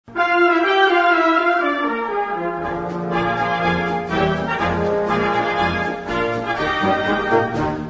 musique d'harmonie